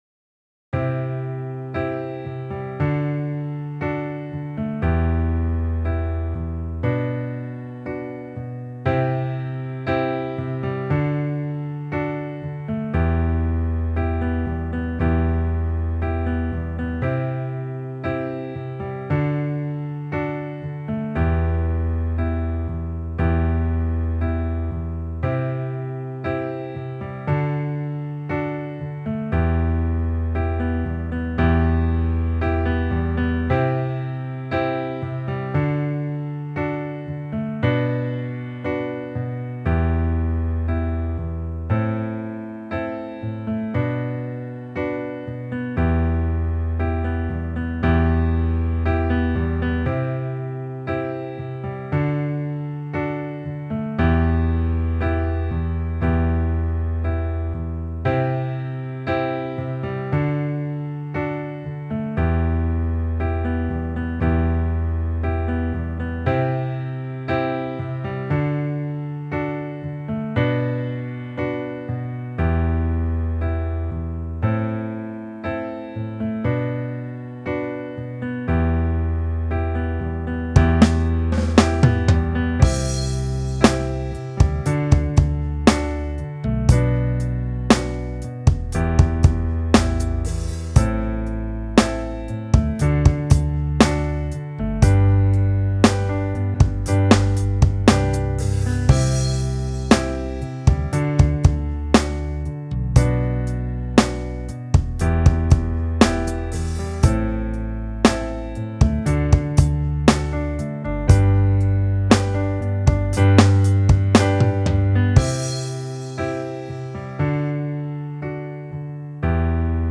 吉他伴奏